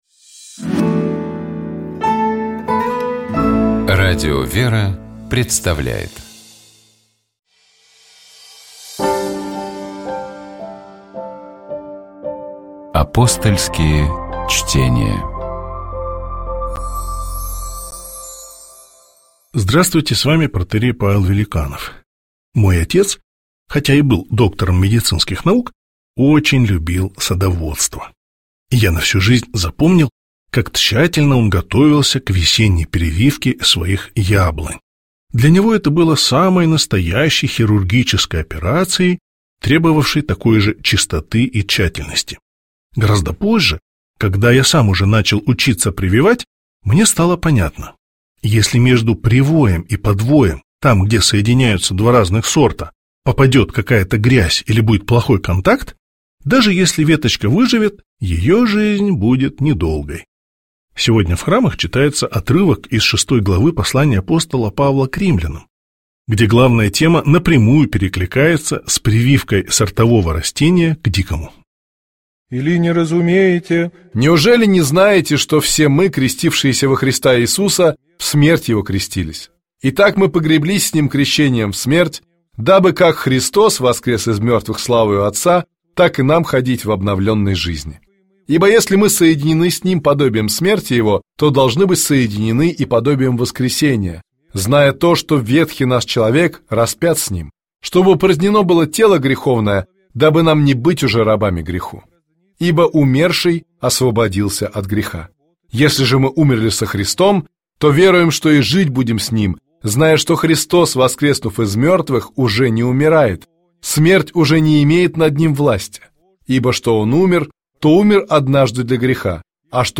Апостольские чтения